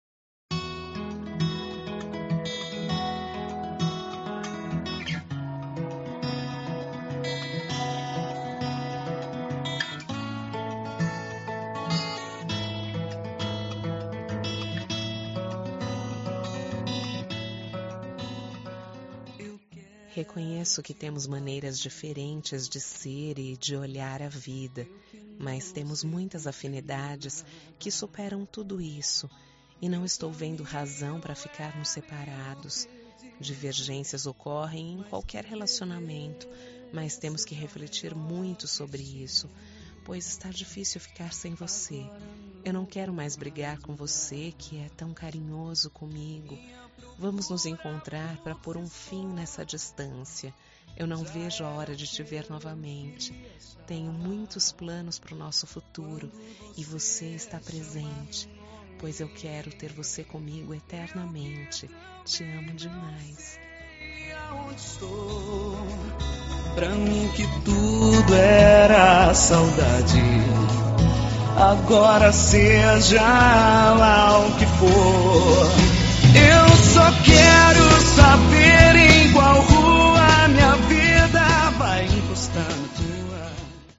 Telemensagem de Reconciliação – Voz Feminina – Cód: 7552